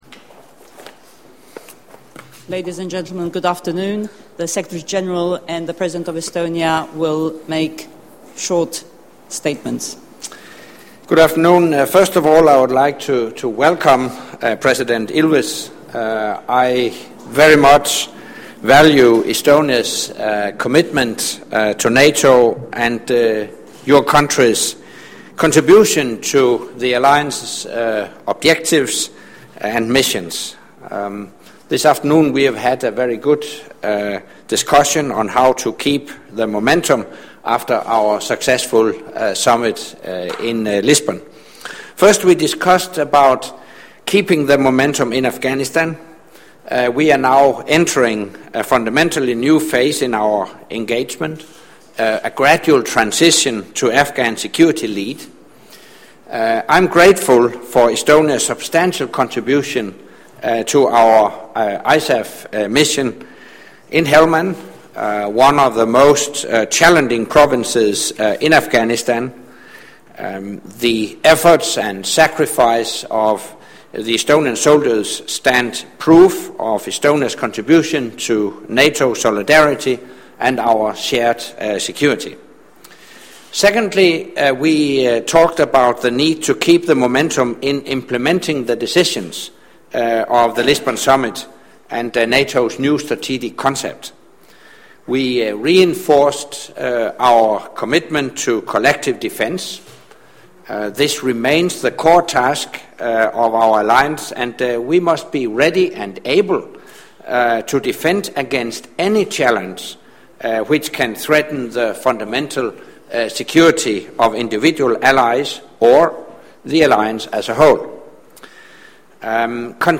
Audio Joint press point with NATO Secretary General Anders Fogh Rasmussen and the President of Estonia, opens new window